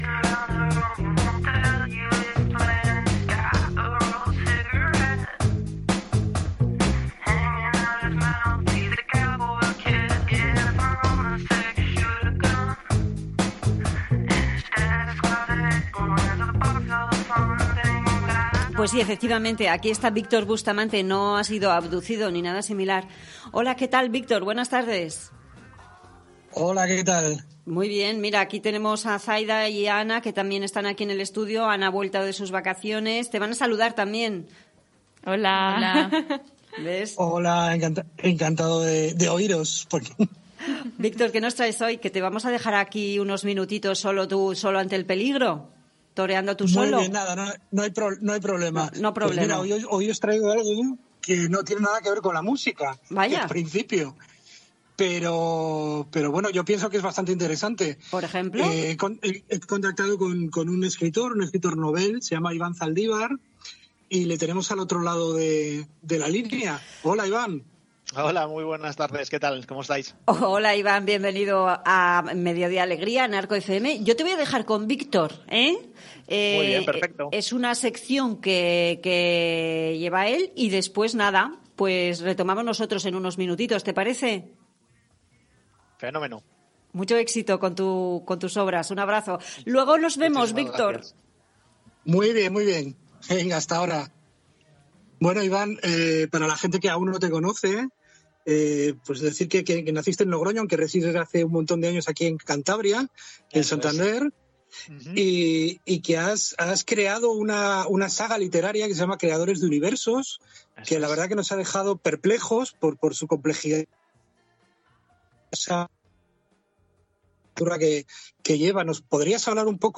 Entrevista en el programa "A mediodía alegría" de ArcoFM en la que se presenta la saga Creadores de universos y sus dos primeros libros.